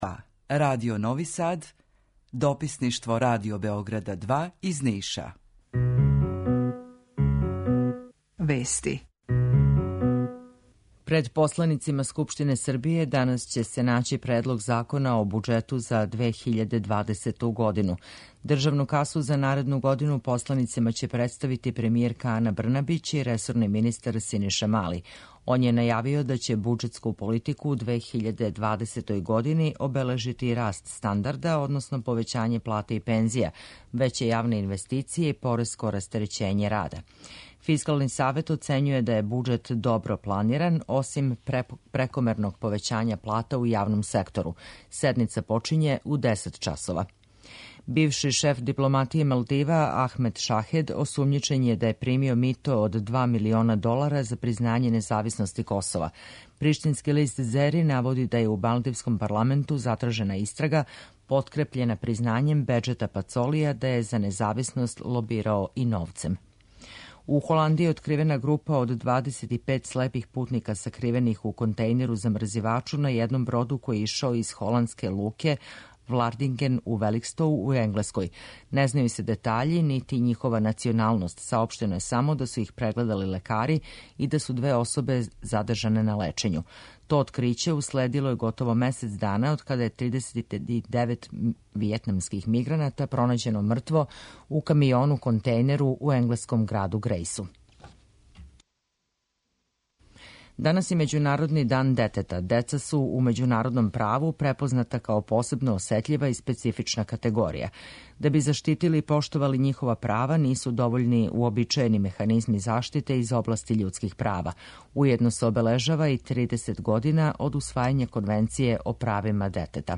Укључење Радио Грачанице